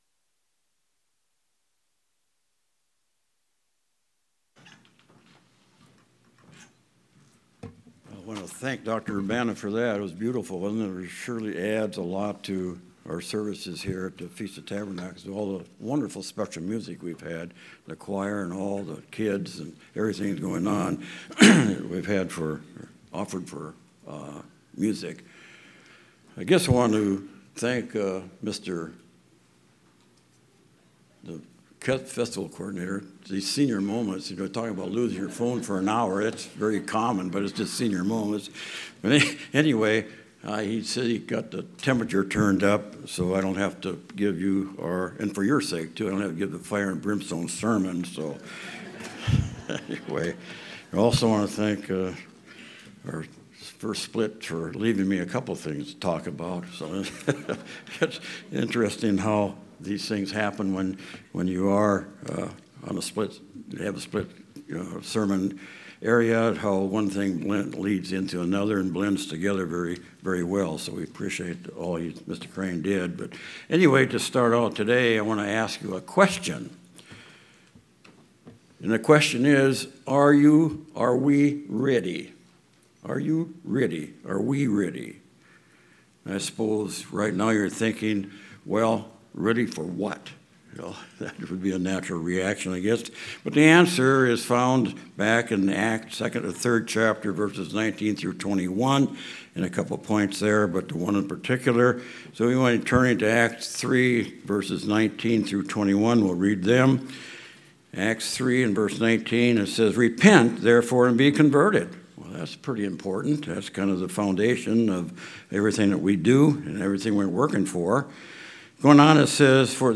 What are some of those qualities needed for that restoration process to occur? Three qualities we need for the future are brought out in this split sermon.
This sermon was given at the Galveston, Texas 2023 Feast site.